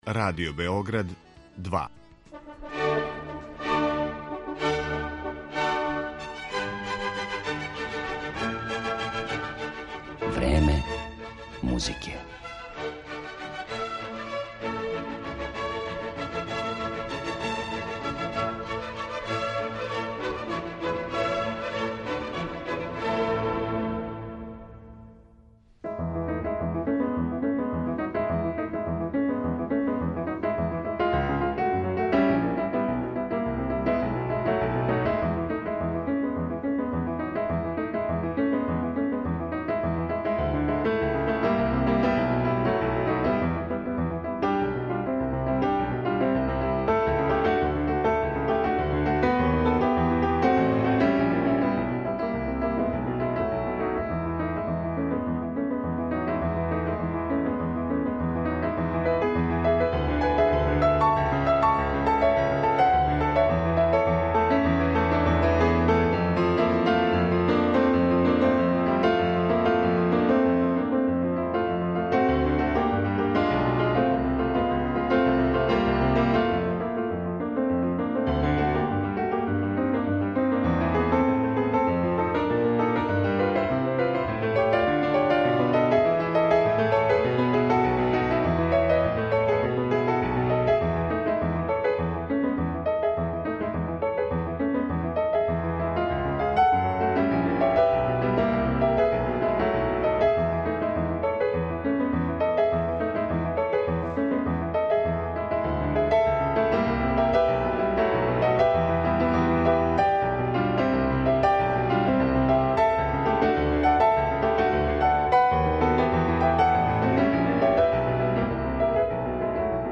У емисији ће такође бити речи и о програму Dream Images који је представила у Америци, као и о другим темама које заокупљају ову уметницу раскошног пијанизма и широког репертоара ‒ од барокних, до композиција савремених српских аутора. Посведочиће о томе и избор дела које ћете данас слушати.